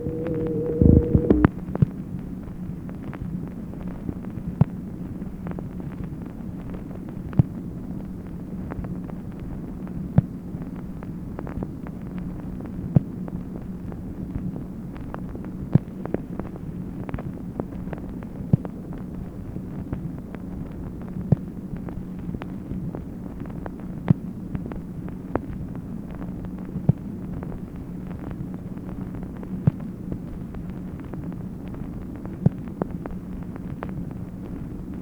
MACHINE NOISE, February 7, 1964
Secret White House Tapes | Lyndon B. Johnson Presidency